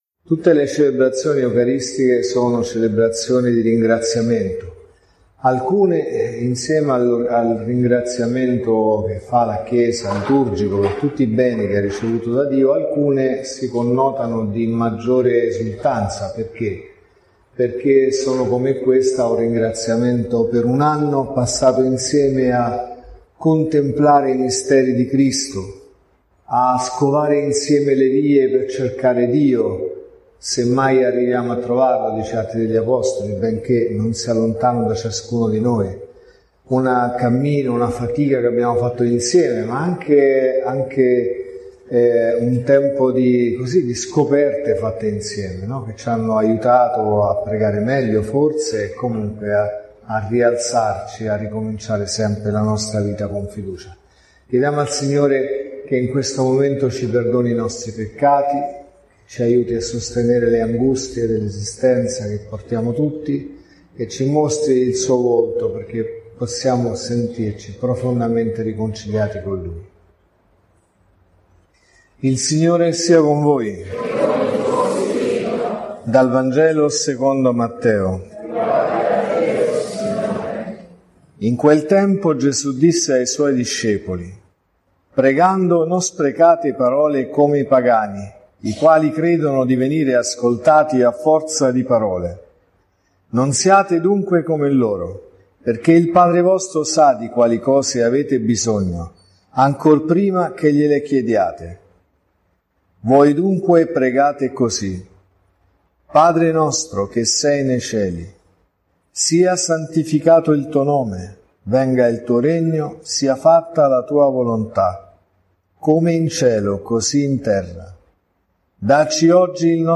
| Omelie LETTURE: Vangelo, Prima lettura e Seconda lettura Dal Vangelo secondo Matteo (Mt 6,7-15) .